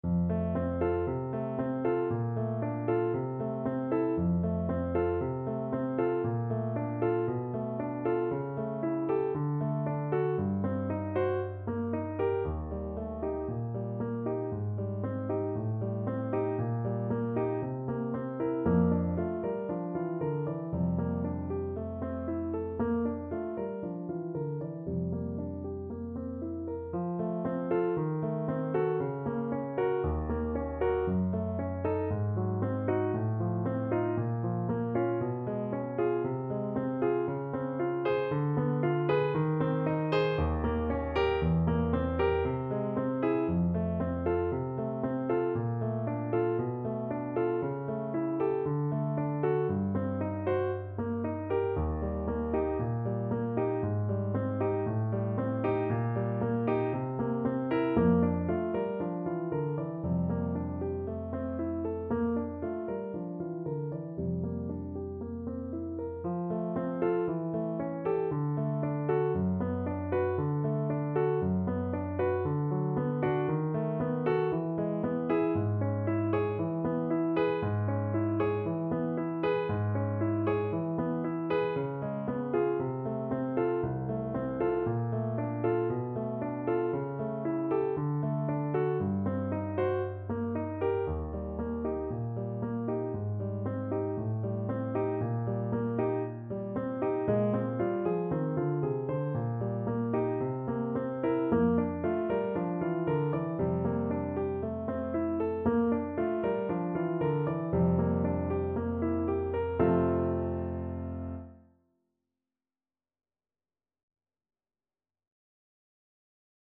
Allegro moderato =116 (View more music marked Allegro)
Classical (View more Classical Voice Music)